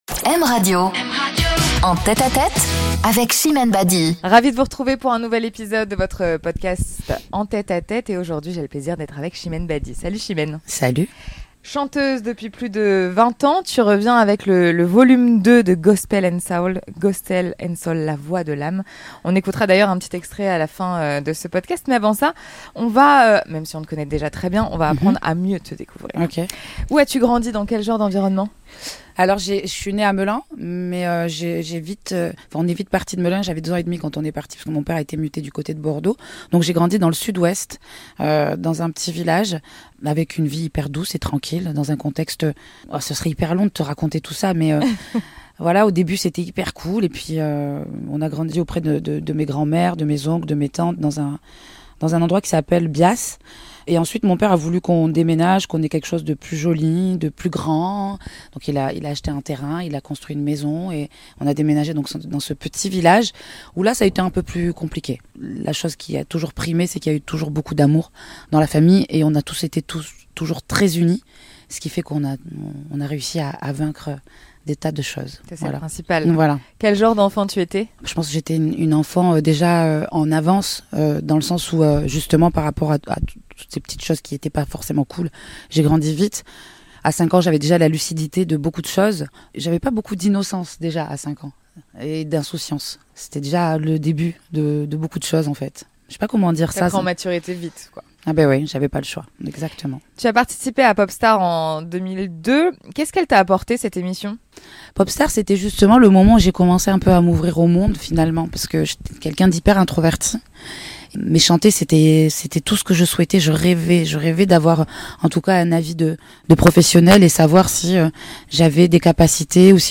Une interview en confidence, dans l'intimité des artistes Chimène Badi de retour avec un nouvel album et prochainement en tournée dans toute la France